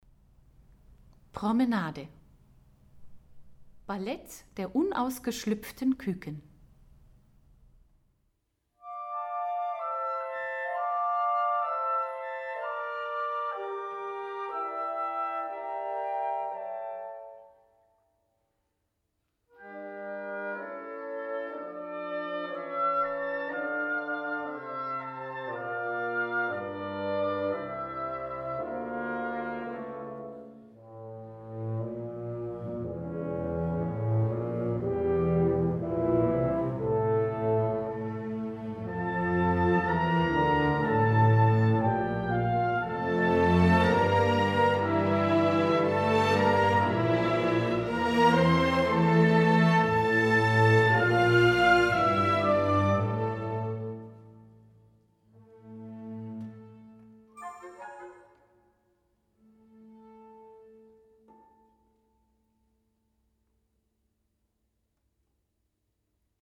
Hör dir die Musik „Bilder einer Ausstellung“ von Modest Mussorgsky, gespielt von der Philharmonie Salzburg an.